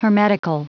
Prononciation du mot hermetical en anglais (fichier audio)
Prononciation du mot : hermetical